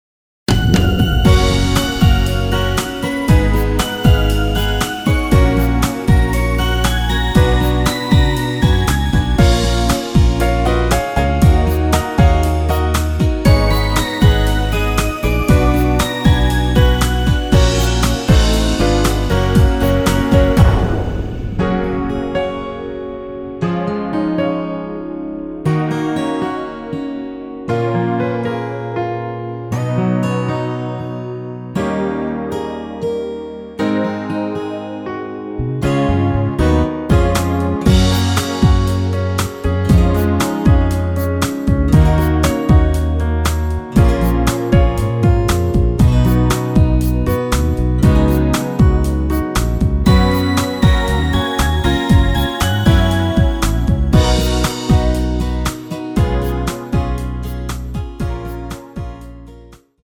축가에 잘 어울리는 곡 입니다.
원키에서(+3)올린 MR입니다.
F#
앞부분30초, 뒷부분30초씩 편집해서 올려 드리고 있습니다.